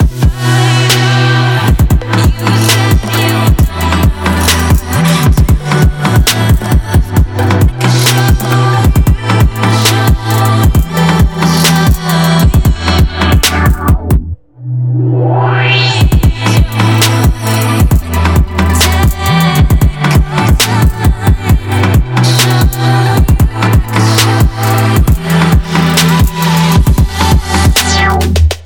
EDM/TRAP（トラップ）のミックス・ビート・トラックのループ素材です。
各ループ素材はBPM（テンポ）を134に統一した16小節のビート・トラックとなっています。